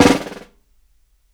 SNARE 2 BUZZ.wav